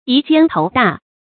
遺艱投大 注音： ㄧˊ ㄐㄧㄢ ㄊㄡˊ ㄉㄚˋ 讀音讀法： 意思解釋： 見「遺大投艱」。